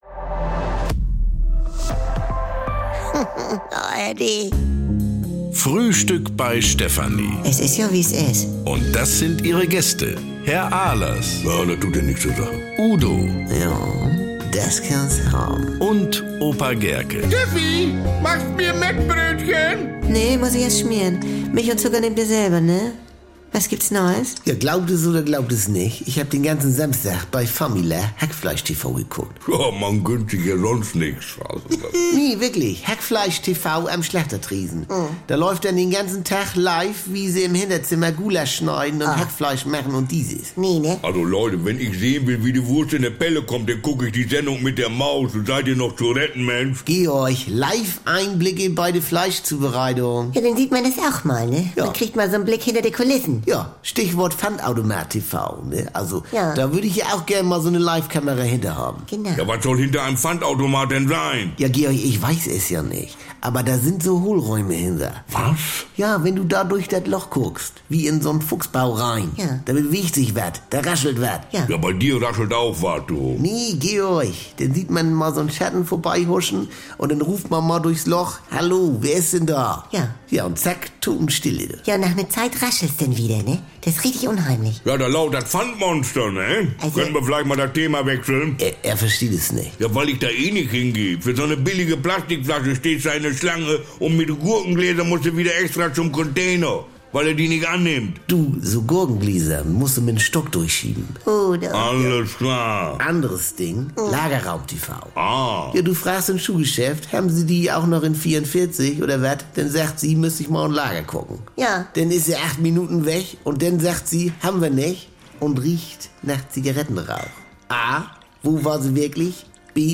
Udo ist begeistert über Live-Einblicke in die Fleischherstellung und hat weitere Live-Ideen. Frisch geschmierte Mettbrötchen, Schnorrer-Tipps, Pyro-Fantasien und brummeliges Gemecker bekommt ihr jeden Tag im Radio oder jederzeit in der ARD Audiothek.